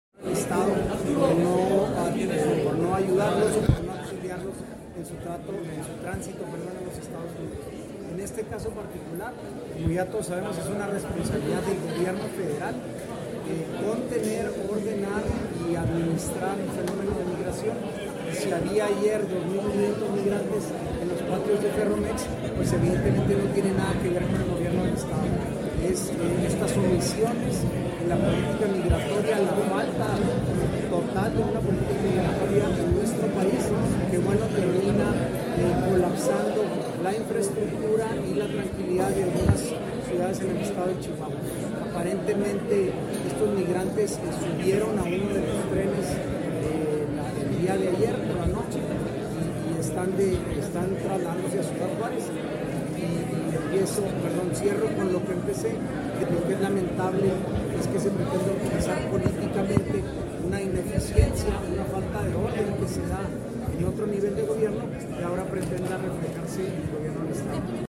AUDIO: SANTIAGO DE LA PEÑA GRAJEDA, SECRETARÍA GENERAL DE GOBIERNO